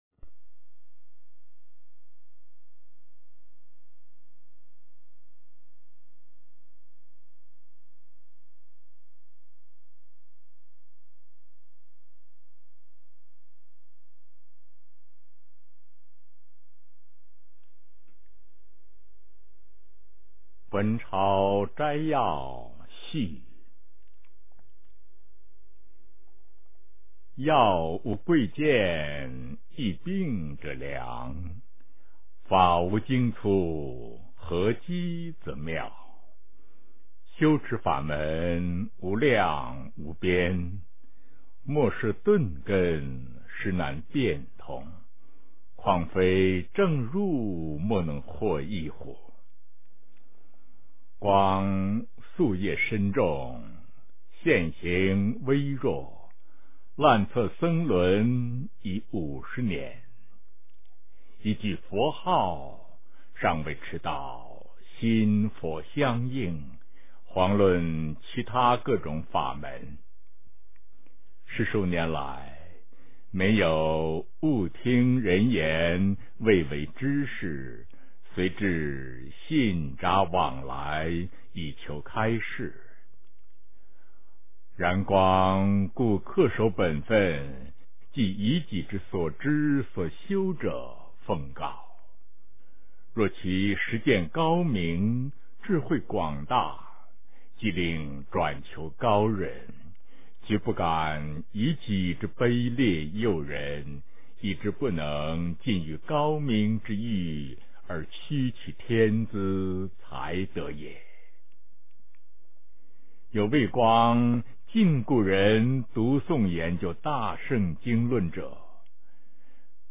诵经 《印光法师文钞》之 弘化人心(上